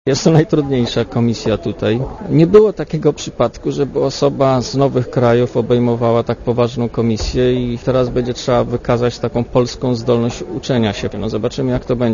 Posłuchaj komentarza Janusza Lewandowskiego